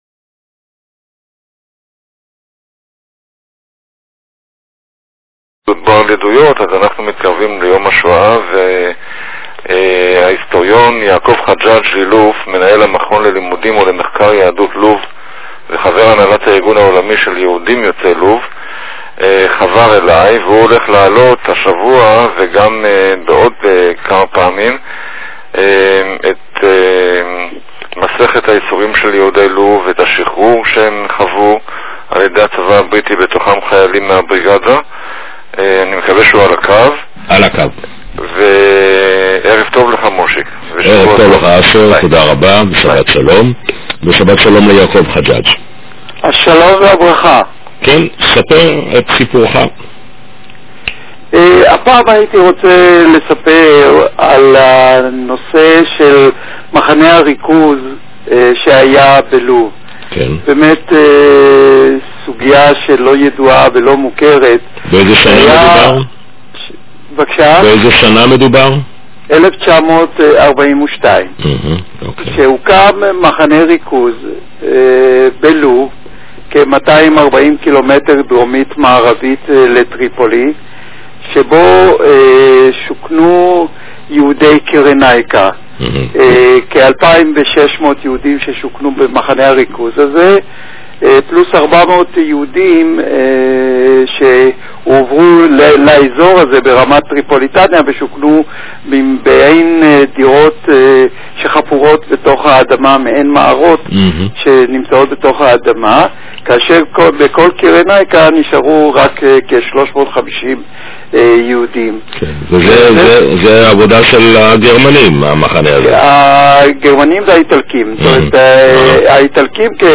ראיון רדיופוני